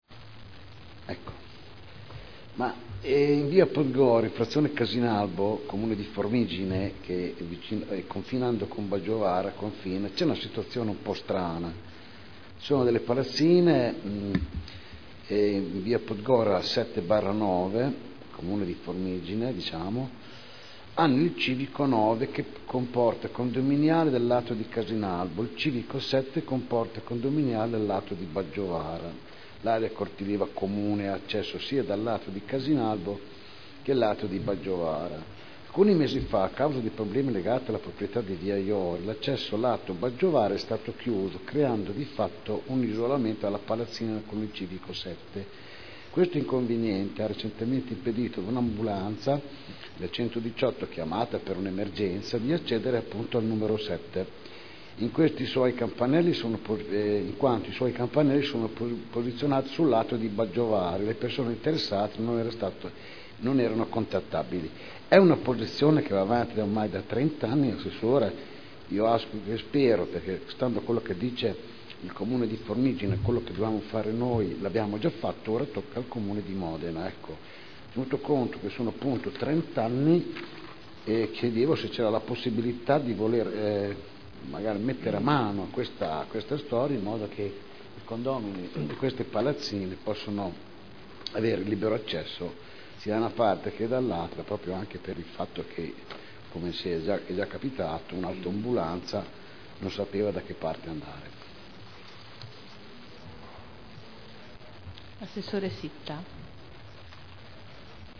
Manfredini - Interrogazione — Sito Audio Consiglio Comunale
Seduta del 09/11/2009. Palazzine site in via Podgora